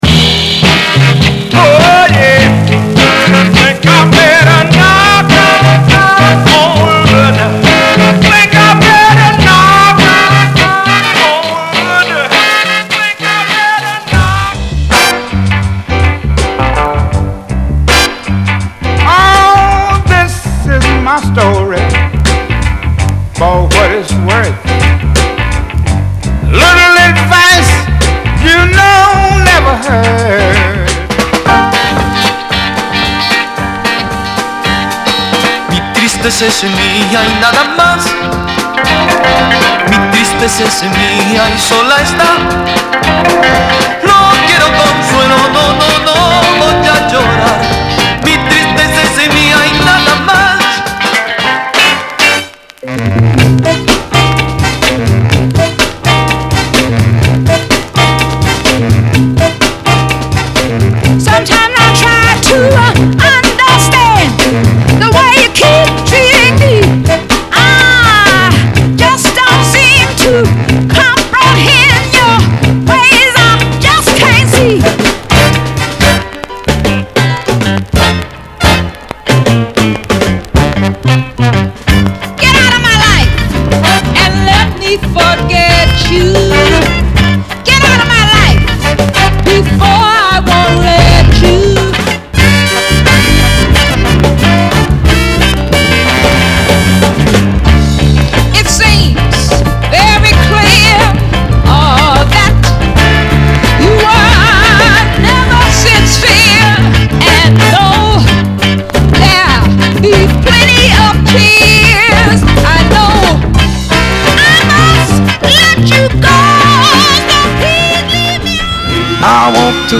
category Blues